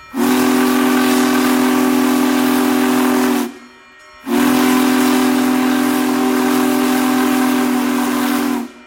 Hundreds of people flocked to the South Manhattan Ave. crossing Wednesday morning for a chance to witness history up close.
Big-Boy-horn-1.wav